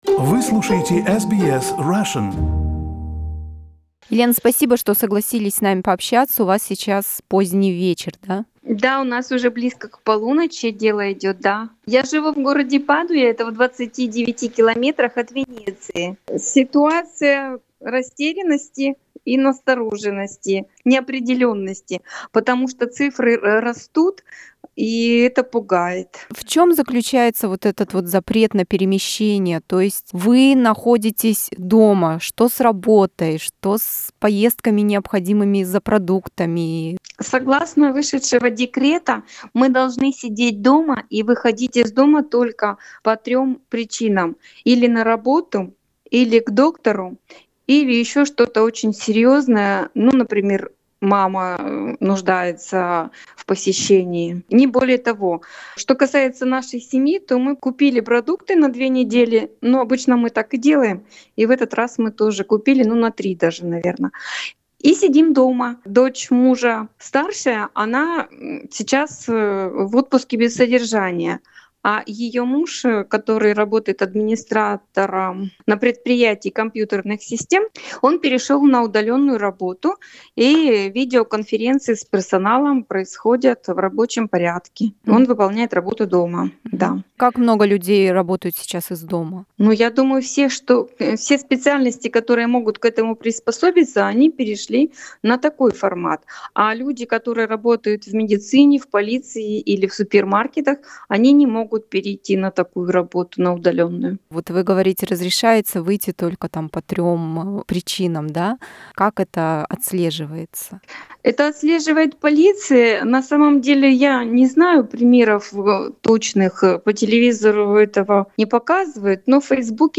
По телефону она рассказала, как это жить в закрытой стране в условиях строгого карантина.